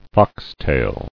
[fox·tail]